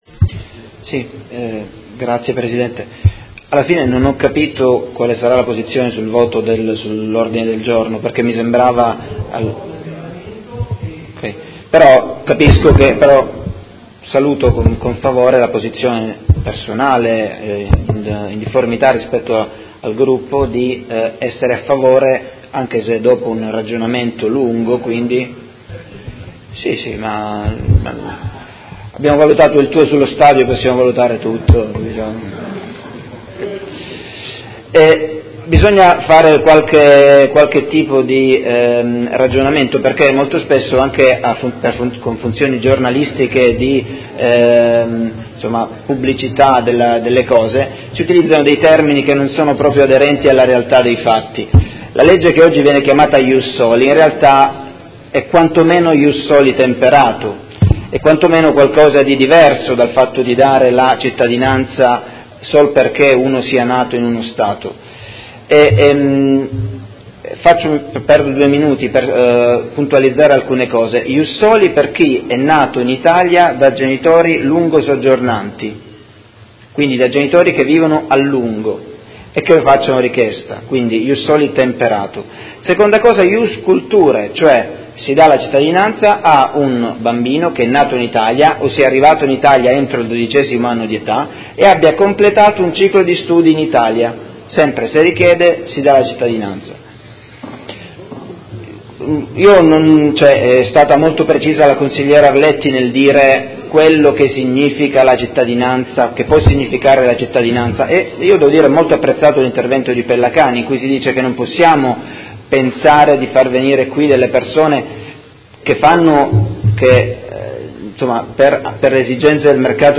Tommaso Fasano — Sito Audio Consiglio Comunale